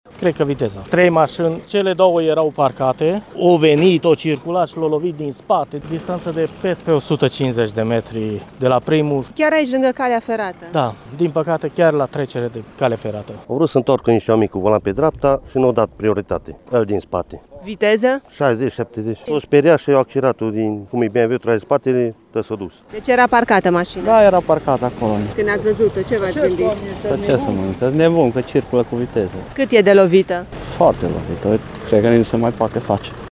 Iată ce declarau martorii oculari ai evenimentului:
stiri-29-aug-accident-voxuri.mp3